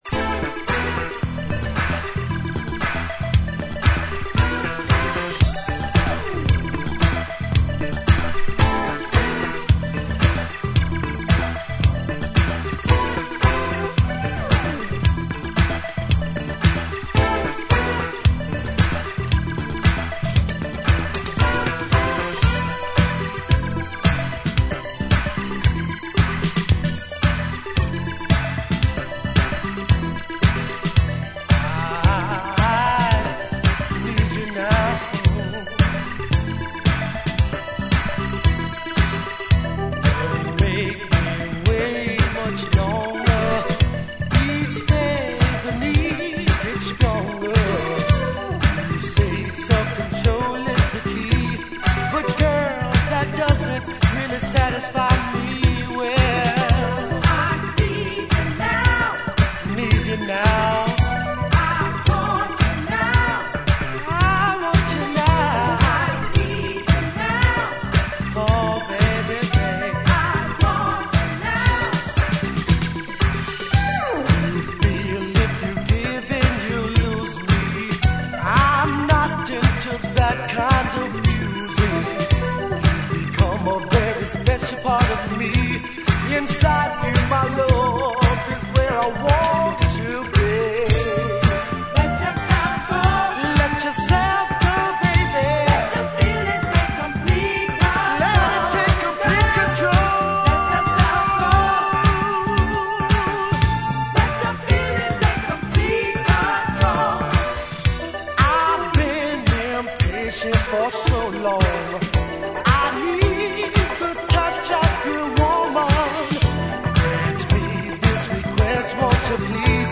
ジャンル(スタイル) DISCO / GARAGE / DANCE CLASSIC